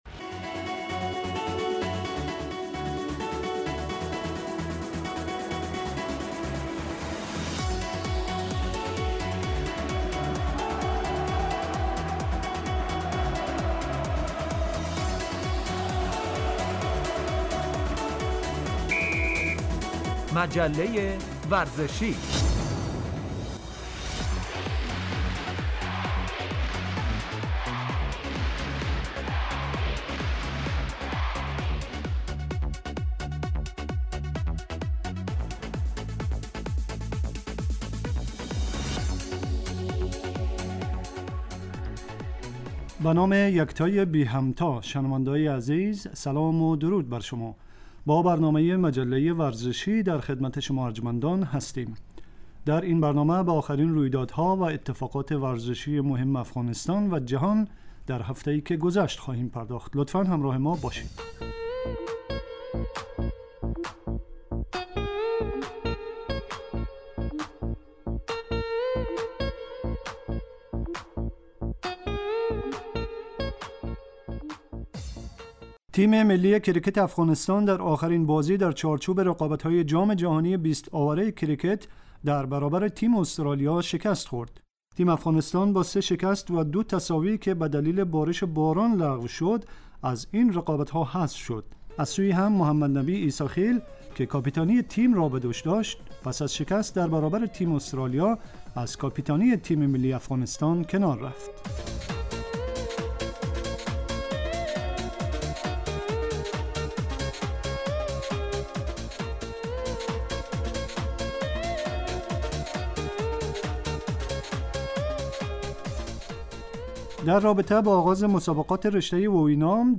آخرين اخبار و رويدادهاي ورزشي افغانستان و جهان در هفته اي که گذشت به همراه گزارش و مصاحبه وبخش ورزش وسلامتي